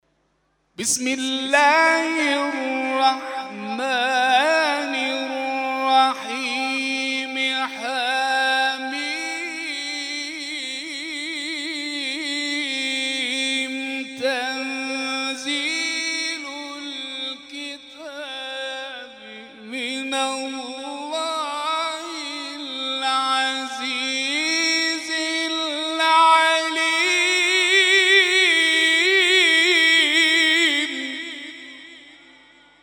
محفل انس با قرآن در آستان عبدالعظیم(ع)+ صوت